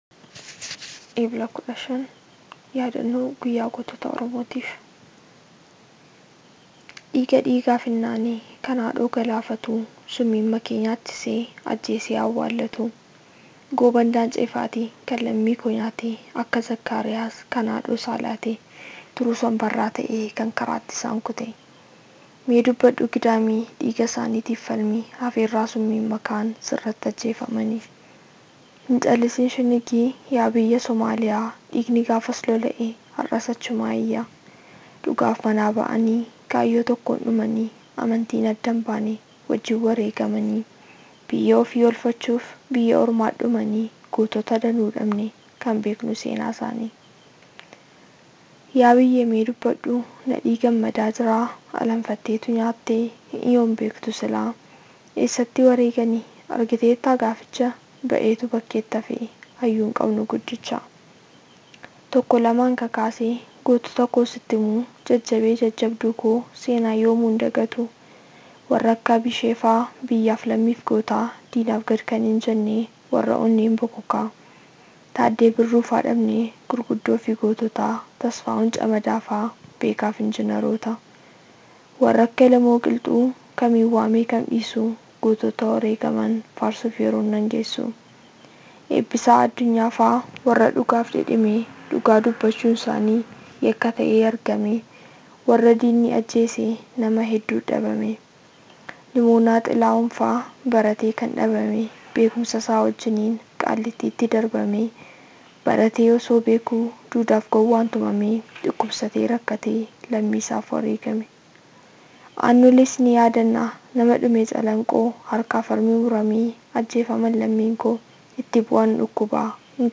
Kabajaa Guyyaa Gootota Oromoo Yuniversitii Wallaggaa Irratti Guyyaa Ha’raa Walaloo Shamarreen Oromoo Dhiheessite.